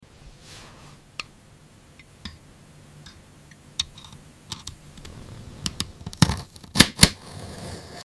part 15 | AI ASMR sound effects free download
part 15 | AI ASMR video for cutting McLaren car